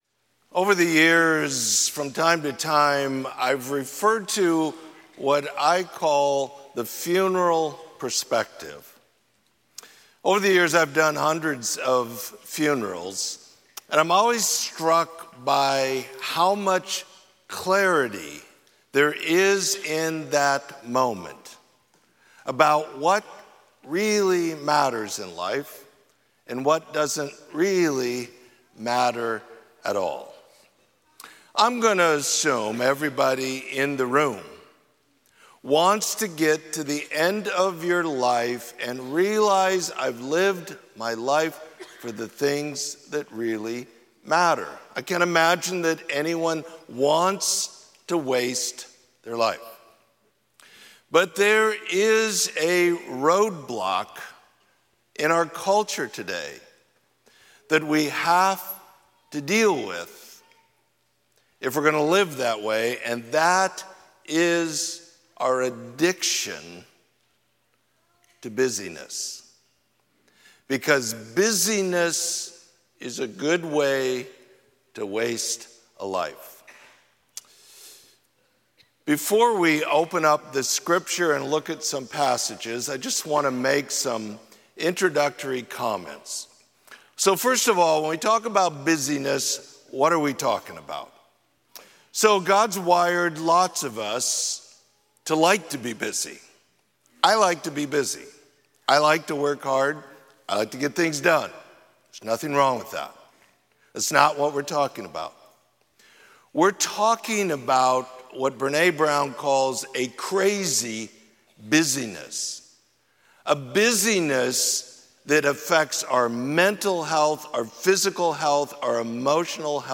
Sermon: Busyness